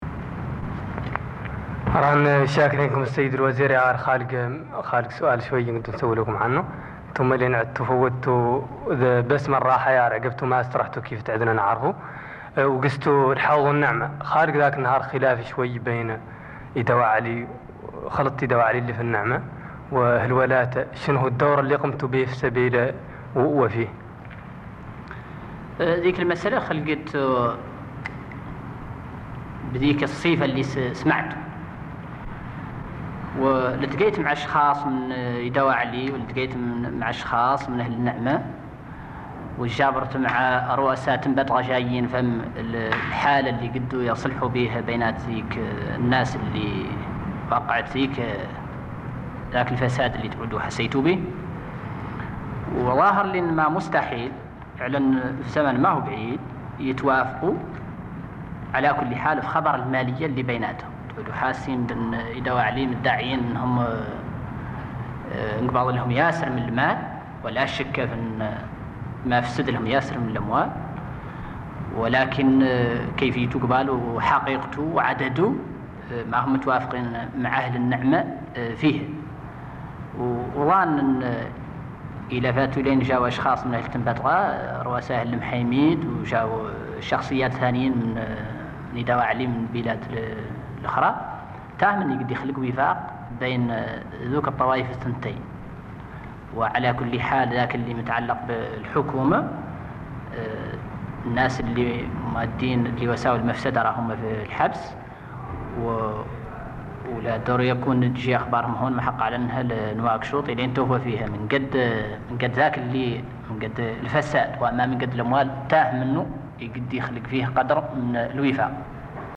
حملنا إليه تسجيلات عتيقة... وبهدوء واهتمام ينصت الوزير السابق شيخنا ولد محمد الأغظف إلى صوت شاب ممتلئ حماسا وثقة بما يقول.
وبالفعل فقد تكاثرت الظباء على خداش، ولم تكن ذاكرة ولد محمد الأغظف التي تمور بمئات المواقف والأحداث لتقف عند فقرة من شريطها الطويل لم تكن إلا تصريحا للإذاعة الوطنية تميز بالبساطة والوضوح عن خلاف قبلي "بين خلطة إدواعلي وأهل النعمة".